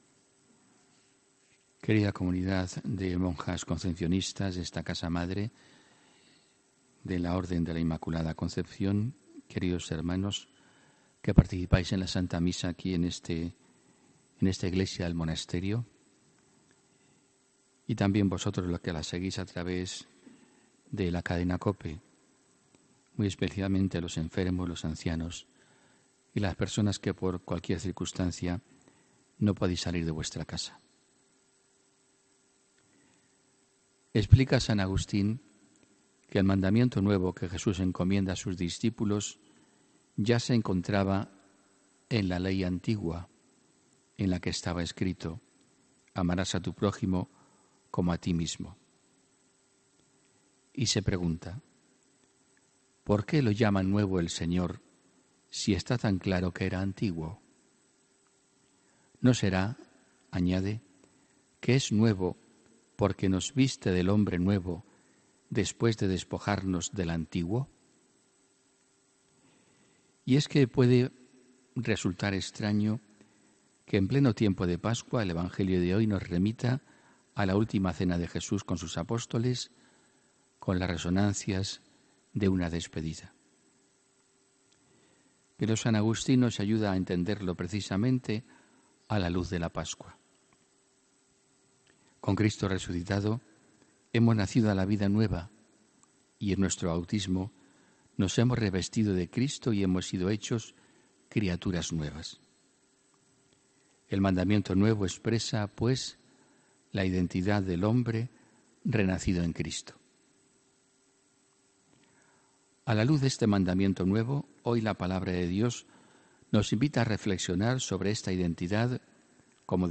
HOMILÍA 19 MAYO 2019